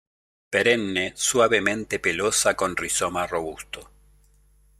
/ˌswabeˈmente/